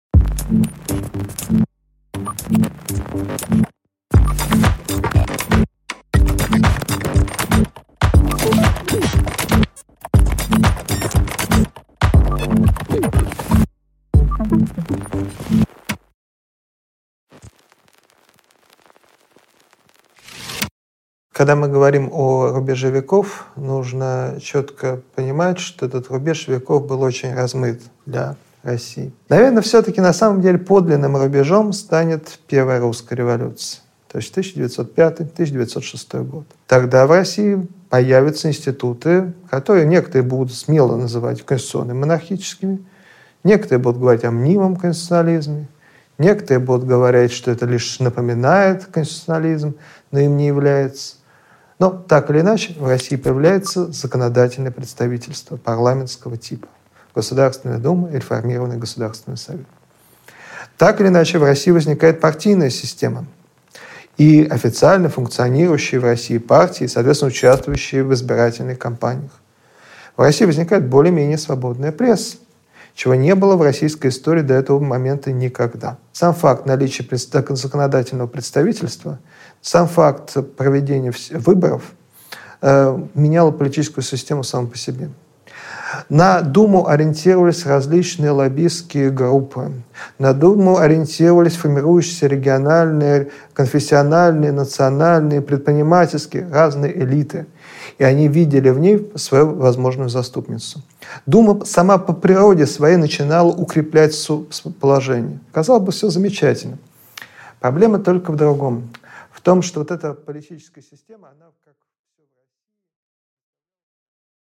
Аудиокнига Между самодержавием и конституцией | Библиотека аудиокниг